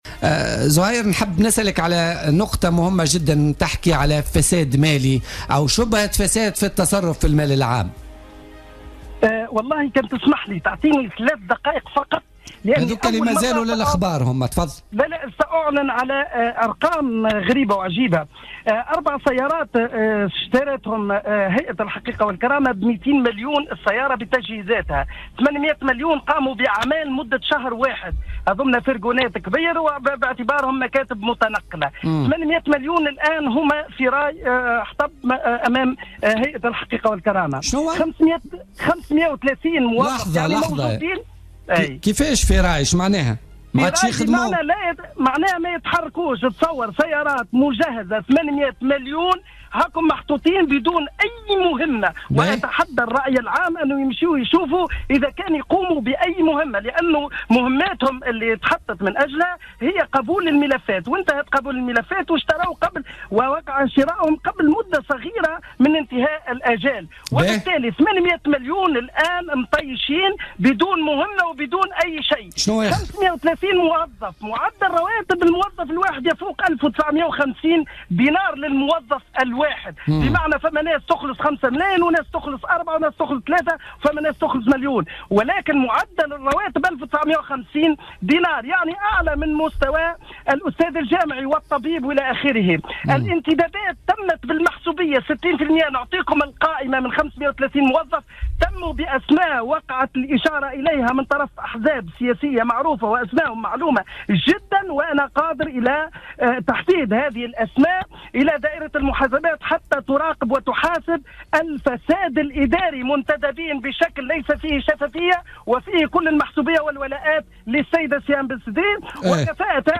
كشف عضو هيئة الحقيقة والكرامة المقال زهير مخلوف في مداخلة له في بوليتيكا اليوم الإثنين 17 أكتوبر 2016 عن أرقام مفزعة للتجاوزات والفساد الحاصل داخل هيئة الحقيقة والكرامة من اهدار واستغلال لا مشروع للمال العام.